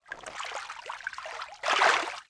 fishing_catch.wav